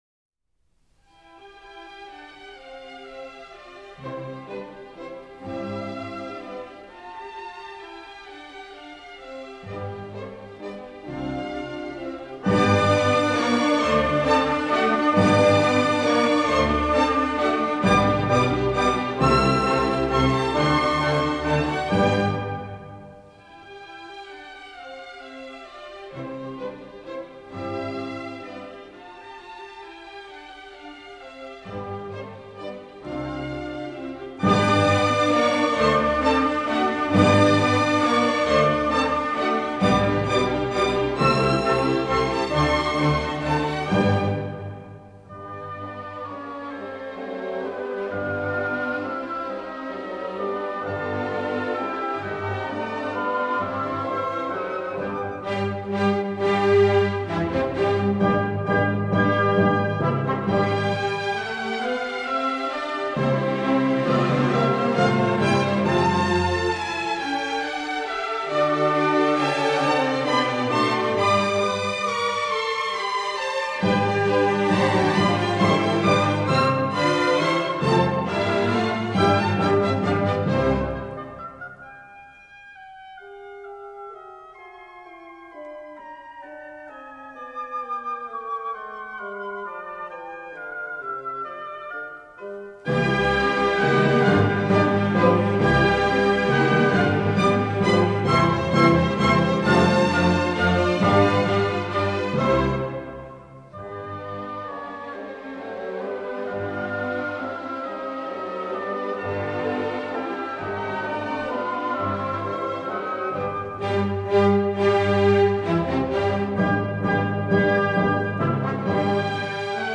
La famosa sinfonia in do magg. detta